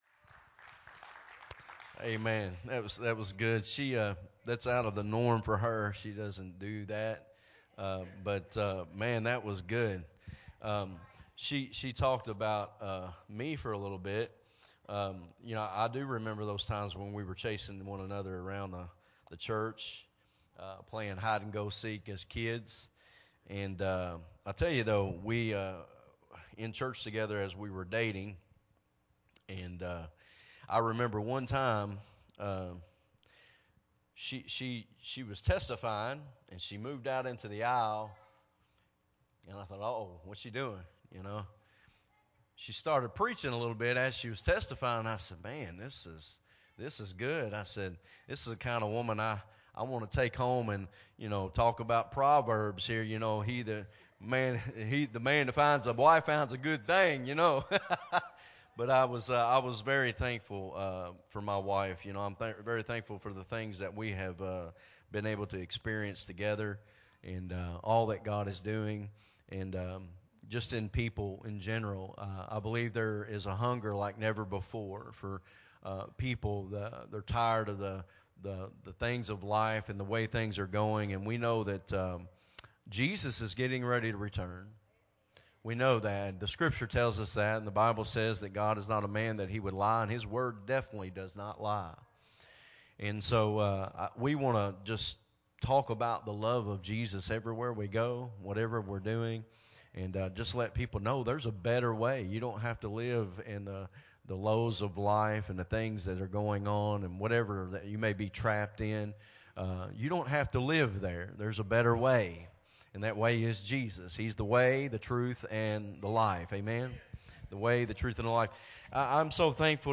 sermon
recorded at Unity Worship Center on July 16, 2022.